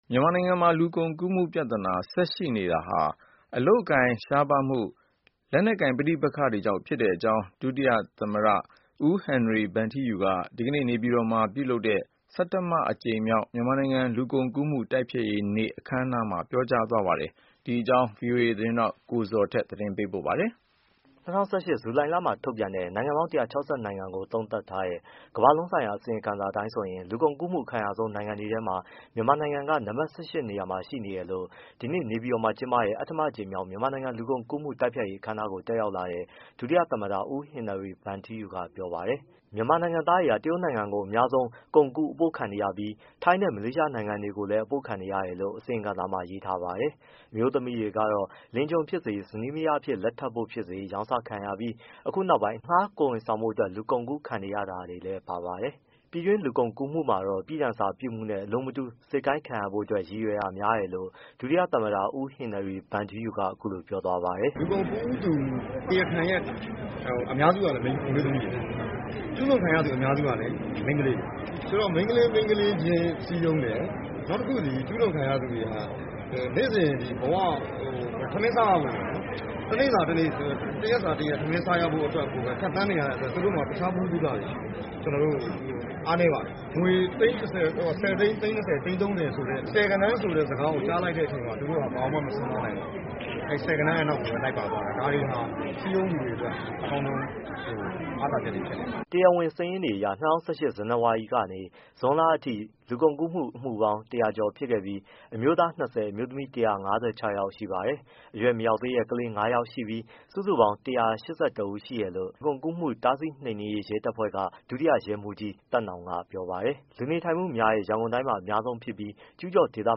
၂၀၁၈ ဇူလိုင်လမှာထုတ်ပြန်တဲ့ နိုင်ငံပေါင်း ၁၆၇ နိုင်ငံကိုသုံးသပ်ထားတဲ့ ကမ္ဘာလုံး ဆိုင်ရာ အစီရင်ခံစာ အတိုင်းဆိုရင် လူကုန်ကူးမှု အခံရဆုံးနိုင်ငံတွေထဲမှာ မြန်မာနိုင်ငံက နံပတ် ၁၈ နေရာမှာရှိတယ်လို့ ဒီနေ့ နေပြည်တော်မှာ ကျင်းပတဲ့ ဆဋ္ဌမအကြိမ်မြောက် မြန်မာနိုင်ငံလူကုန်ကူးမှု တိုက်ဖျက်ရေး အခမ်းအနားကို တက်ရောက်လာတဲ့ ဒုတိယ သမ္မတ ဦးဟင်နရီဗန်ထီးယူက ပြောပါတယ်။